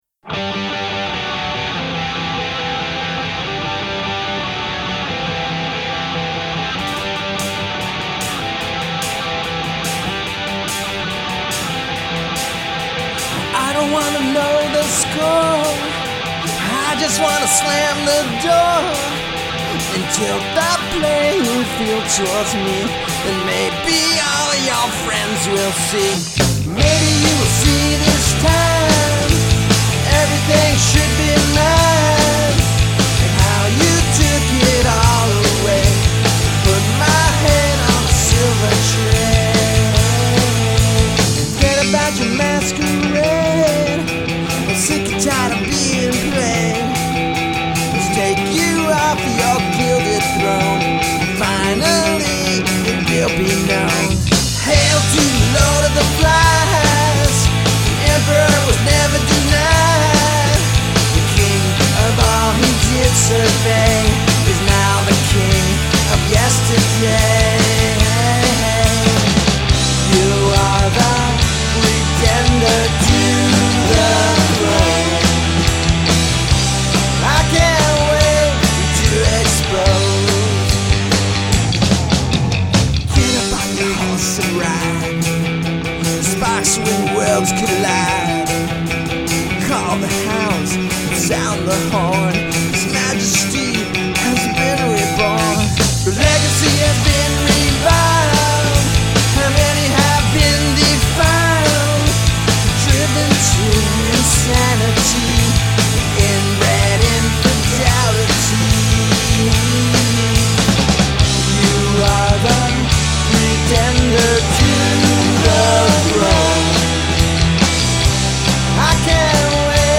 Guitar & Vocal
Drums
Bass & Vocal
Recordsed at Tru One Studios